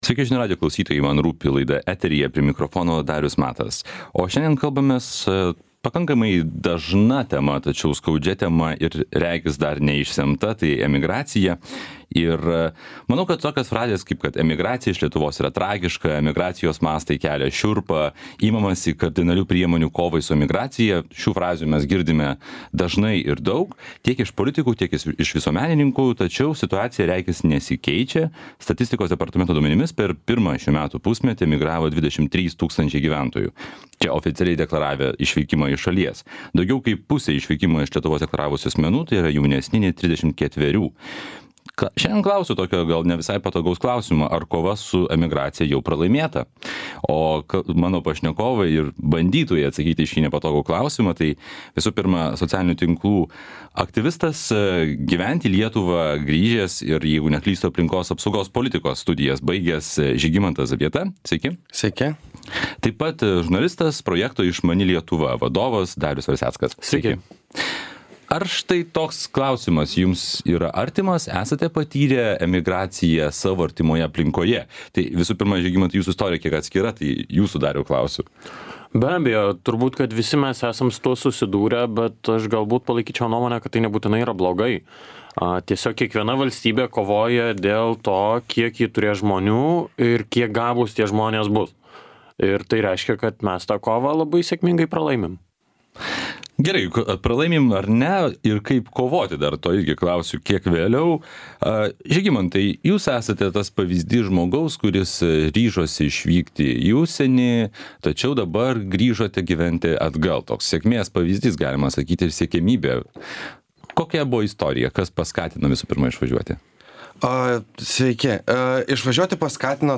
Ar kova jau pralaimėta? Pokalbis su socialinių tinklų aktyvistu